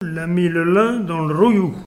Nieul-sur-l'Autise
Catégorie Locution